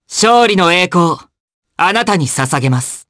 Fluss-Vox_Victory_jp.wav